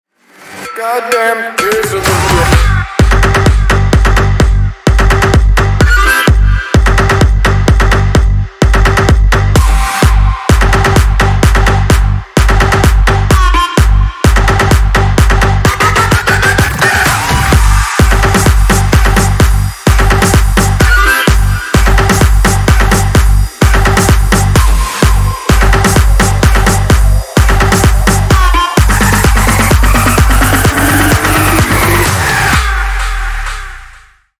Электроника
без слов